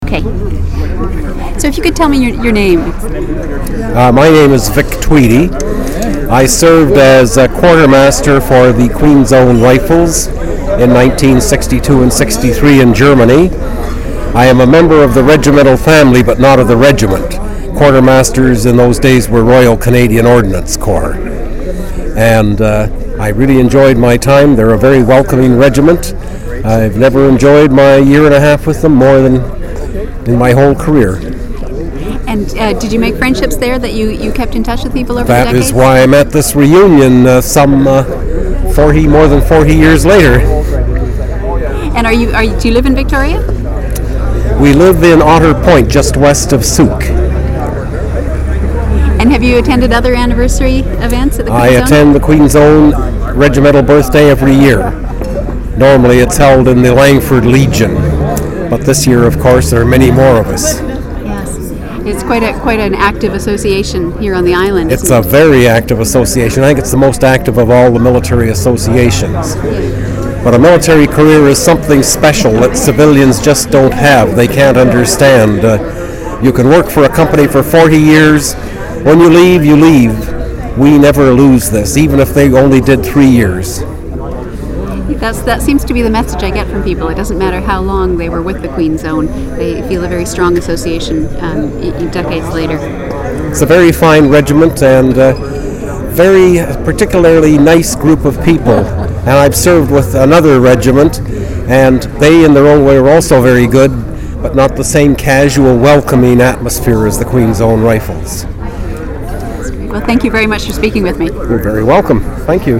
Interviewer
• Interview took place during the Queen's Own Rifles of Canada Vancouver Island Branch 150th Anniversary Celebration.
Recorded in digital format by interviewer, technical and cataloguing metadata provided by JF and JP.